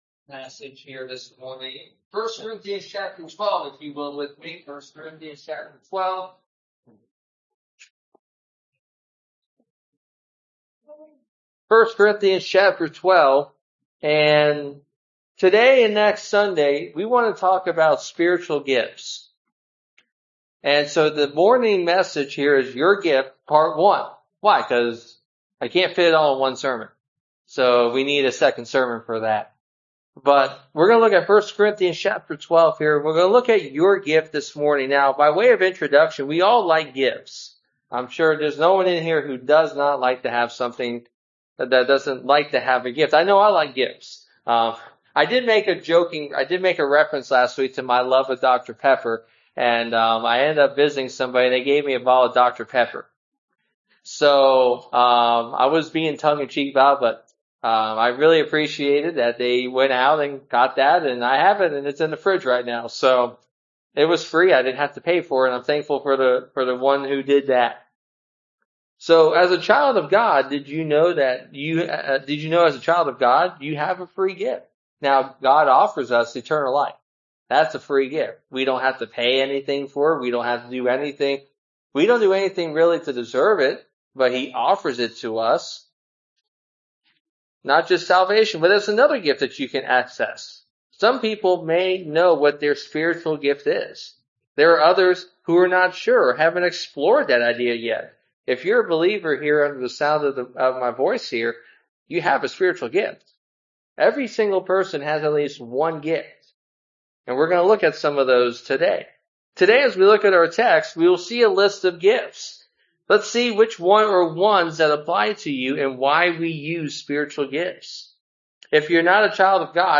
Passage: 1 Corinthians 12 Service Type: Sunday Morning (voice only)